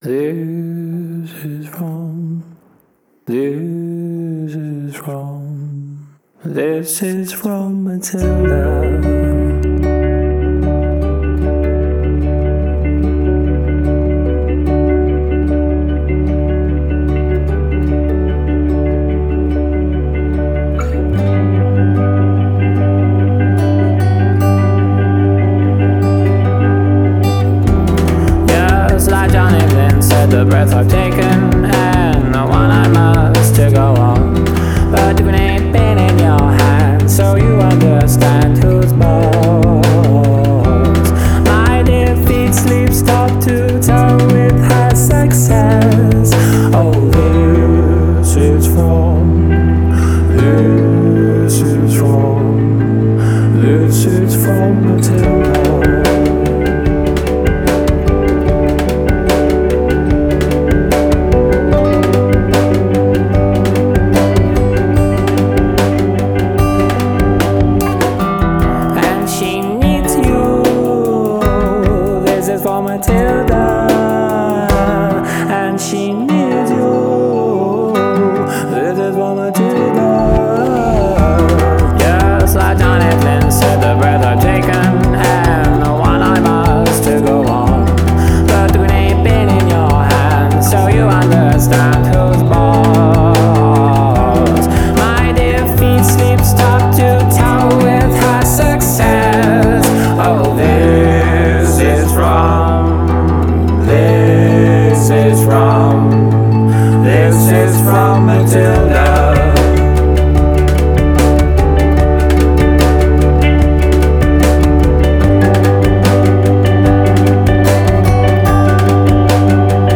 Art Rock